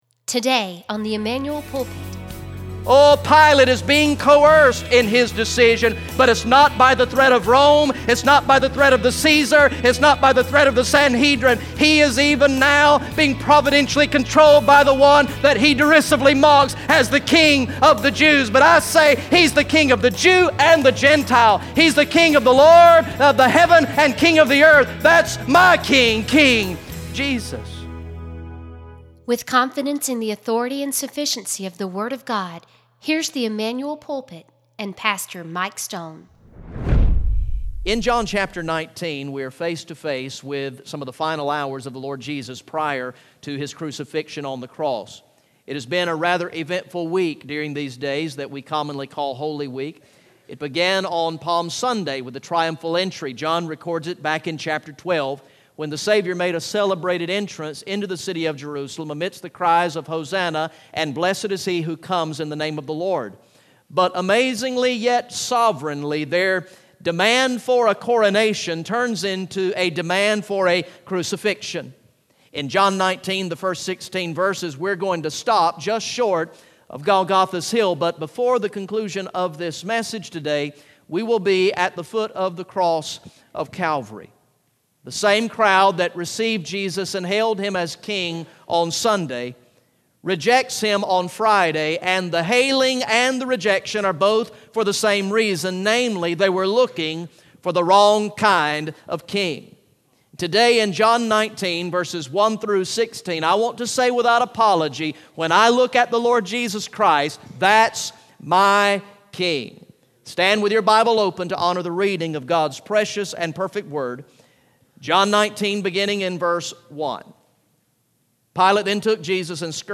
Message #70 from the sermon series through the gospel of John entitled "I Believe" Recorded in the morning worship service on Sunday, August 28, 2016